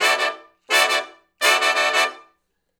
065 Funk Riff (D) har.wav